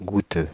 Ääntäminen
Ääntäminen France: IPA: /ɡu.tø/ Paris Haettu sana löytyi näillä lähdekielillä: ranska Käännös Konteksti Ääninäyte Adjektiivit 1. tasty ruoasta US Suku: m .